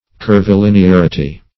Search Result for " curvilinearity" : The Collaborative International Dictionary of English v.0.48: Curvilinearity \Cur`vi*lin`e*ar"i*ty\ (-?r"?-t?), n. The state of being curvilinear or of being bounded by curved lines.
curvilinearity.mp3